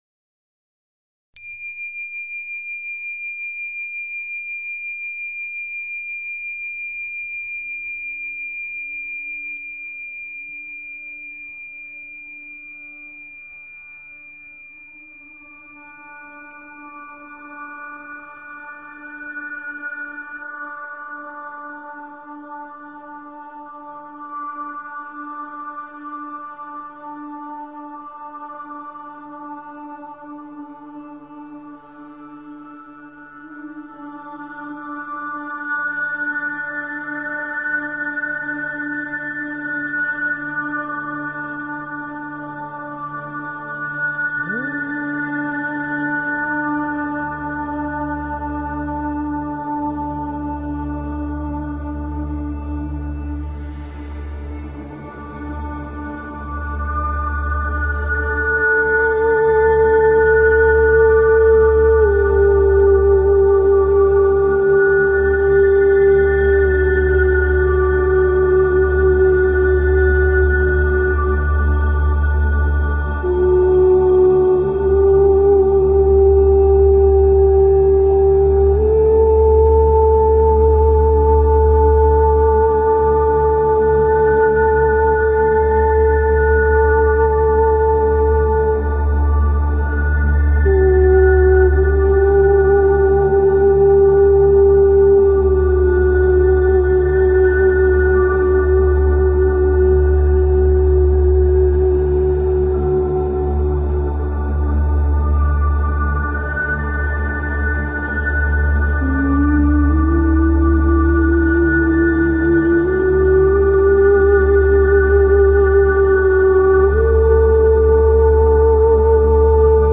Talk Show Episode, Audio Podcast, Radiance_by_Design and Courtesy of BBS Radio on , show guests , about , categorized as
Radiance By Design is specifically tailored to the energies of each week and your calls dictate our on air discussions.